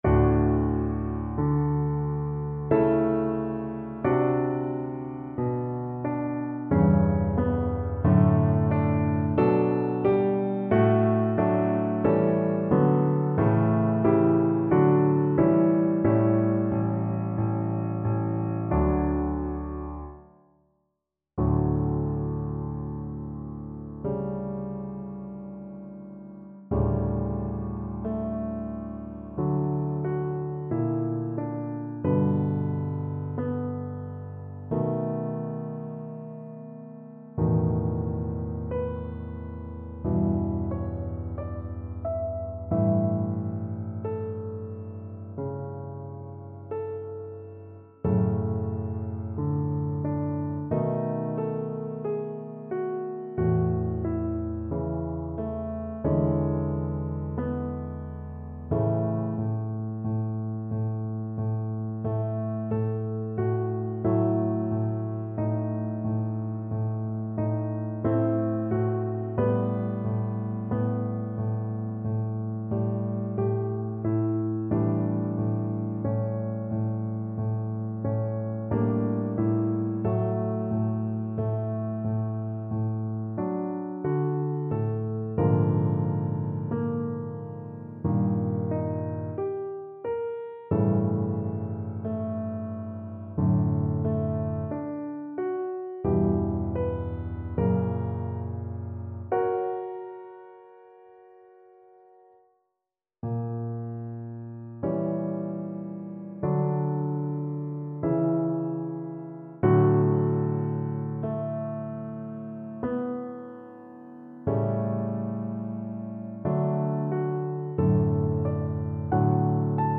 Larghetto =60
Classical (View more Classical Cello Music)